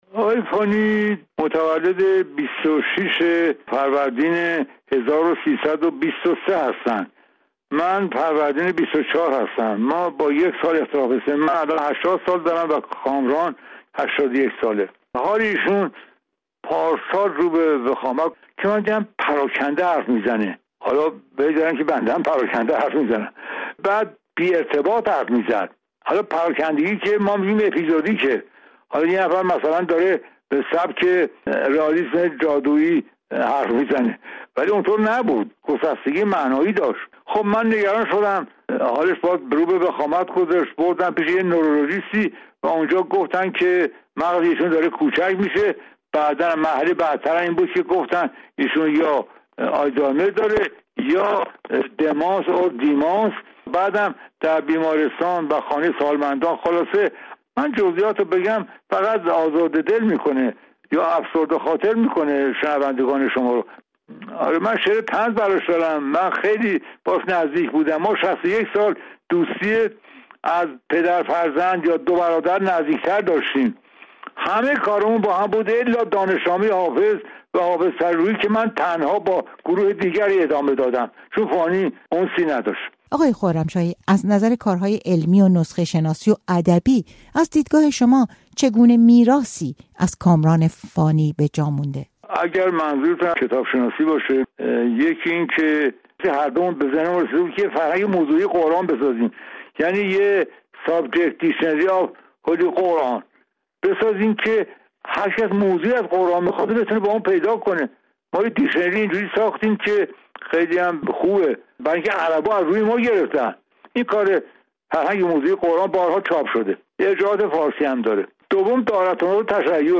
کامران فانی، مترجم و کتاب‌شناس برجسته، روز شنبه در سن ۸۱ سالگی در تهران درگذشت. بهاءالدین خرمشاهی، نویسنده و پژوهشگری که سابقهٔ ده‌ها سال دوستی و همکاری با کامران فانی دارد، در گفت‌وگو با رادیو فردا از آثار و شخصیت او می‌گوید.